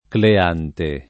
Cleante [ kle # nte ]